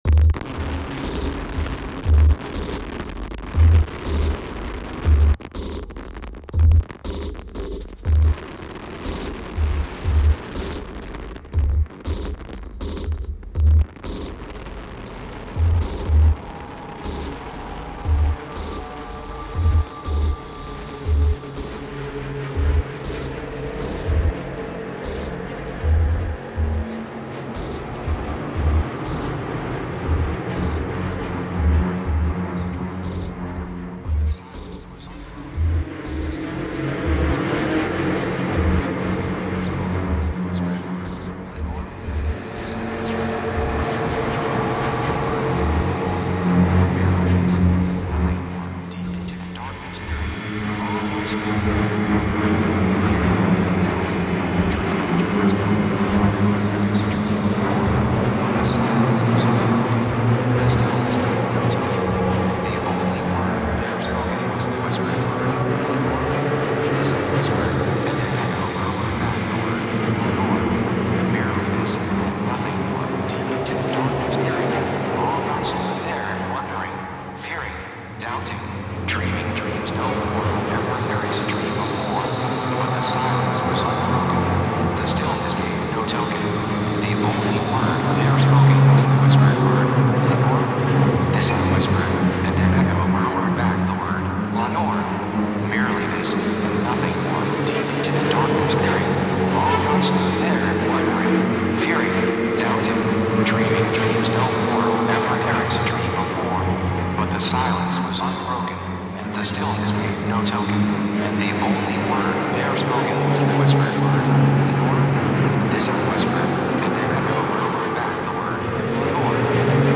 本作は大編成のストリングスと男性合唱からなるサイバー・ゴシックなオーケストレーションと、
デジタル・ハードコアなビートとのアマルガムである。
ヒトに非ざる非実存ナレーターは、MacOS X Leopardによる合成言語。
漆黒のサイバー・ハードコア・ゴシックが鳴る。